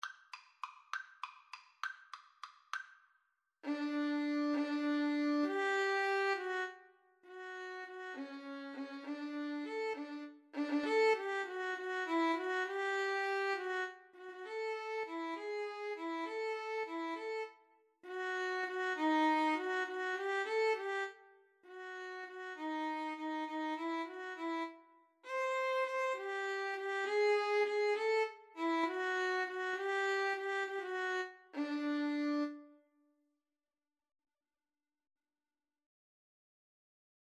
Play (or use space bar on your keyboard) Pause Music Playalong - Player 1 Accompaniment reset tempo print settings full screen
D major (Sounding Pitch) (View more D major Music for Violin Duet )
3/8 (View more 3/8 Music)
Classical (View more Classical Violin Duet Music)